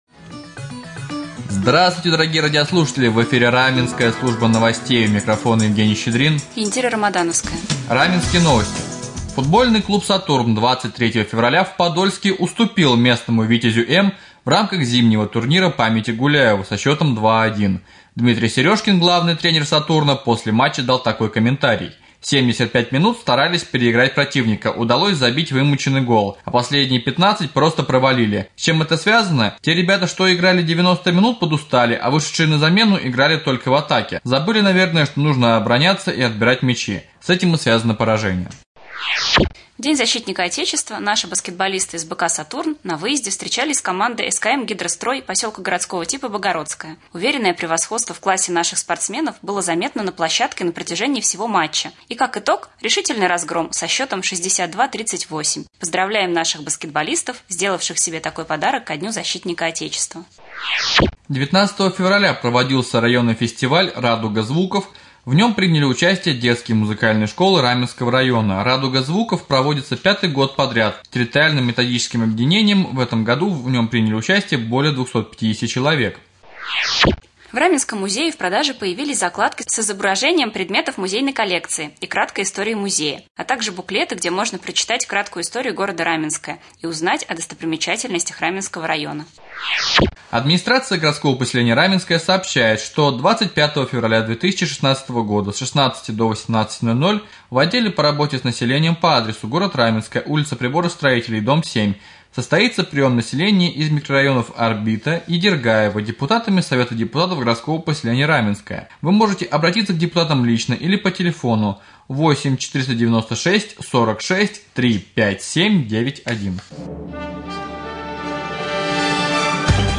1. Прямой эфир с главой с.п.Ганусовское М.В.Овечкиным 2. Новости